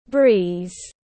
Breeze /briːz/